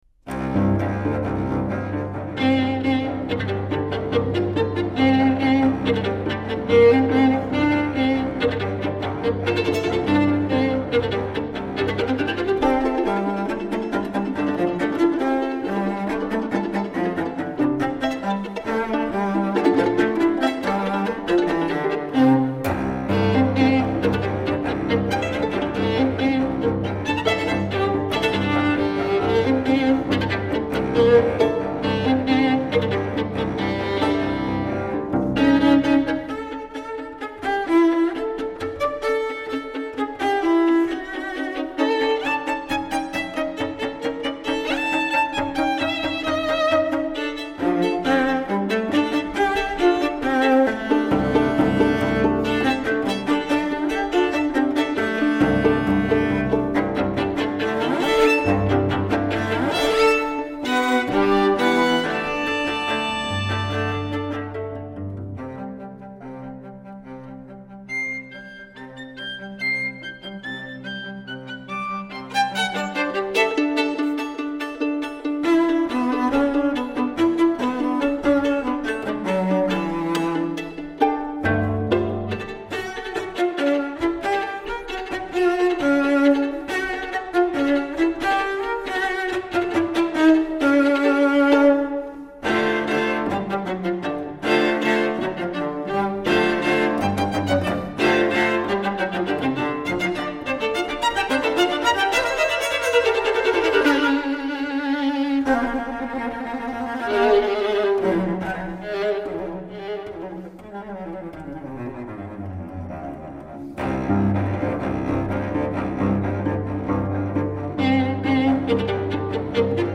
Incontro con la musicista di Locarno